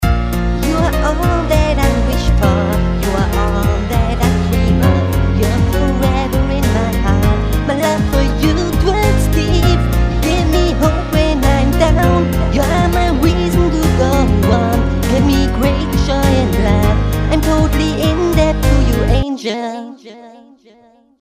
描述：蓝调的说唱旋律
标签： 100 bpm Rap Loops Groove Loops 2.02 MB wav Key : Unknown
声道立体声